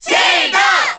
File:Zelda Cheer Korean SSBB.ogg
Zelda_Cheer_Korean_SSBB.ogg.mp3